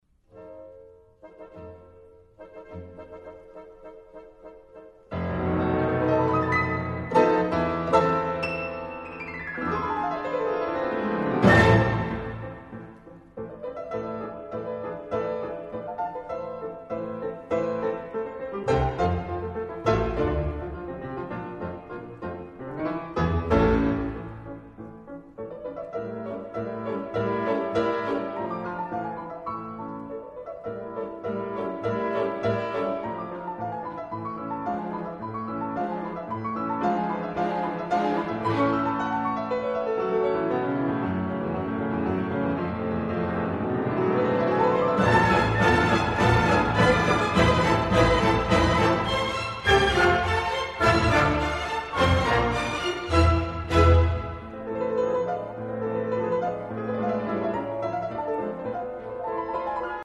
Great Classical Music